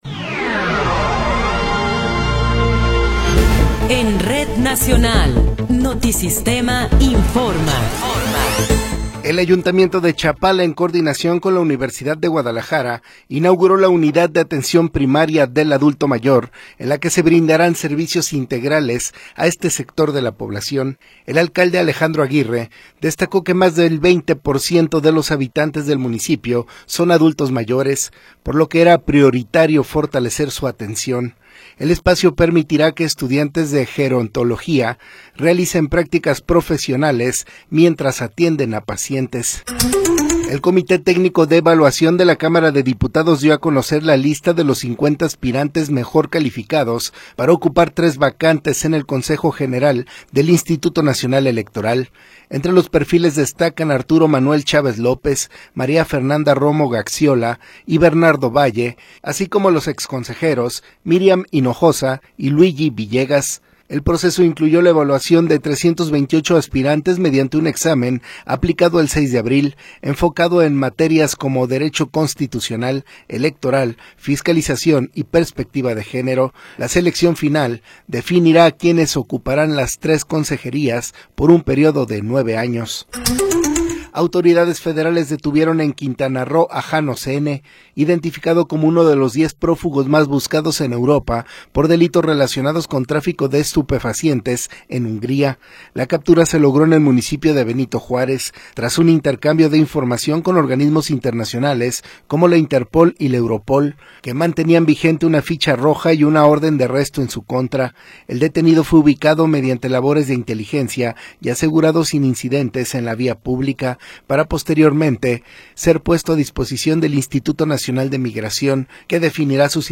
Noticiero 11 hrs. – 18 de Abril de 2026